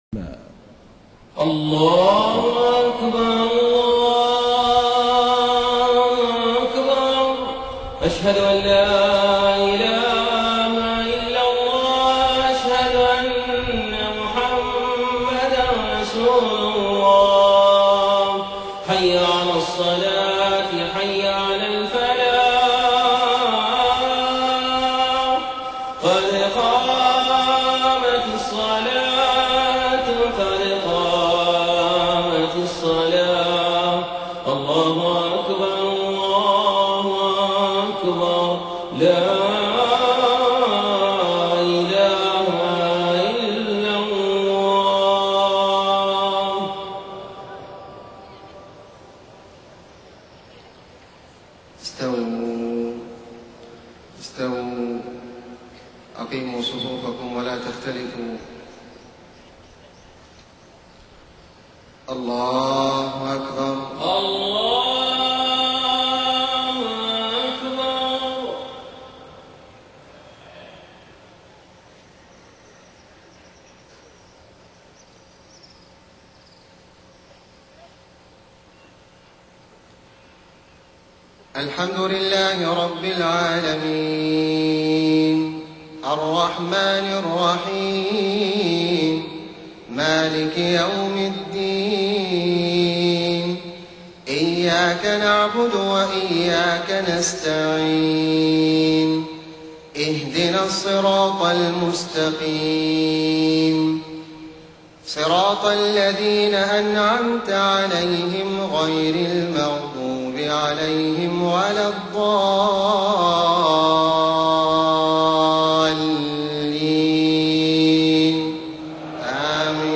صلاة المغرب 4 صفر 1430هـ من سورة المائدة 64 و67-68 > 1430 🕋 > الفروض - تلاوات الحرمين